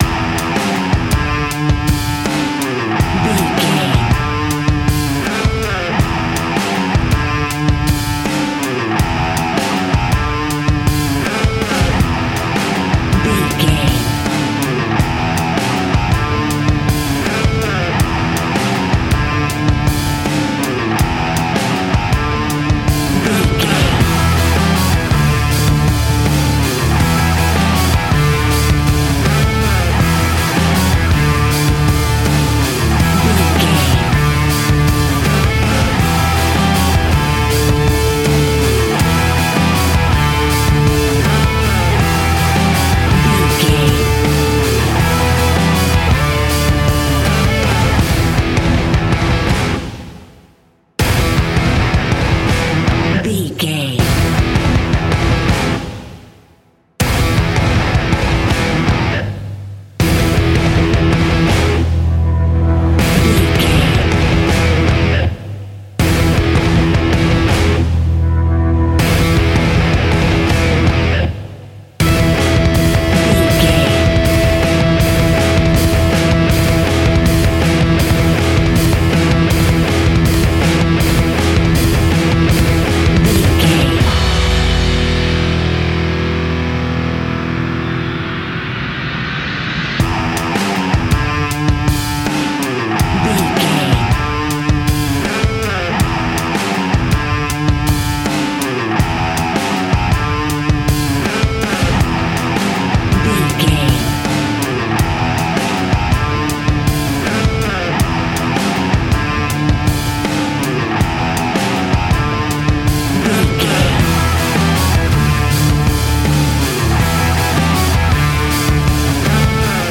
Ionian/Major
E♭
hard rock
heavy rock
distortion
instrumentals